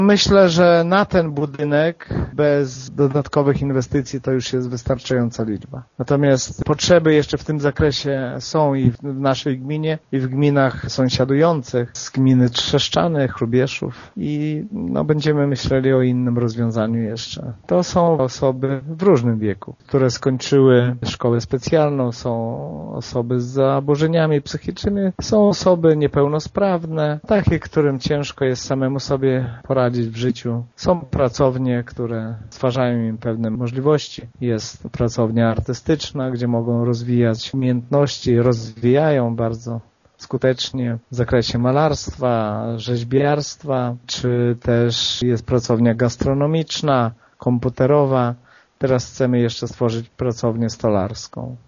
Zdaniem wójta Gminy Mircze Lecha Szopińskiego na razie to powinno wystarczyć: